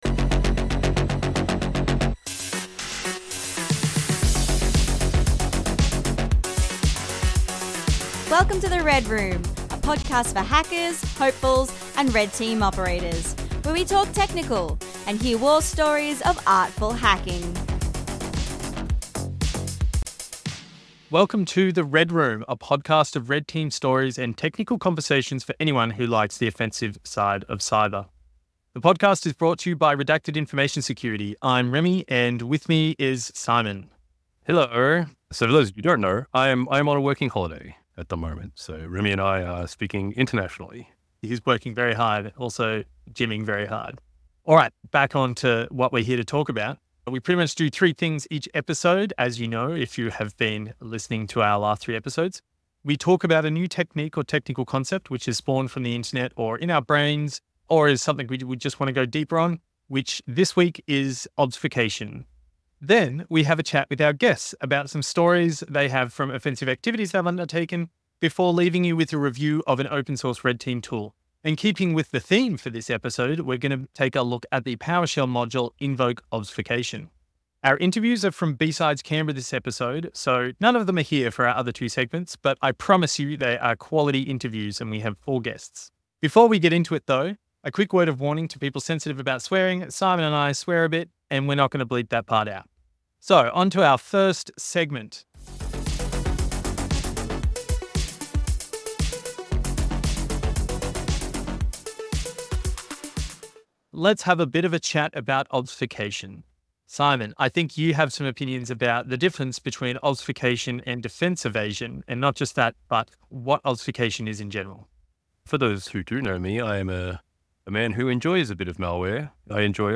We managed to record some of our conversations, and present them here in all their glory.